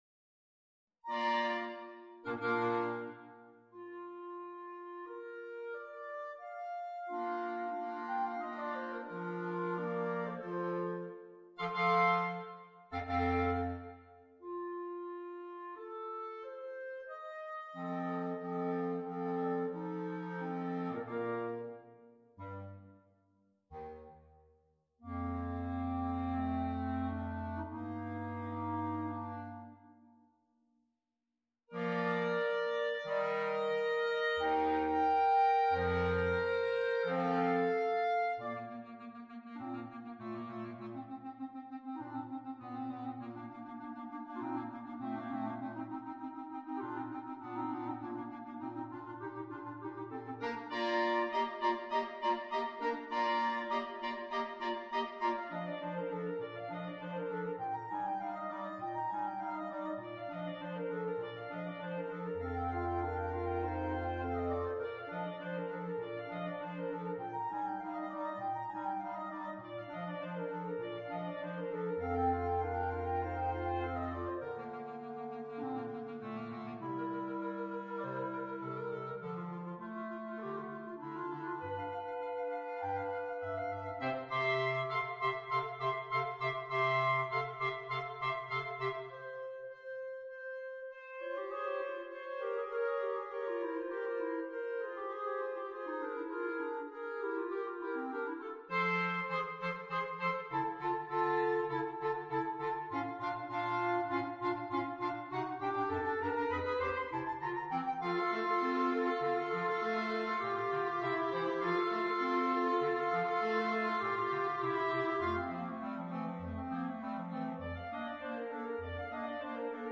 Per quartetto di clarinetti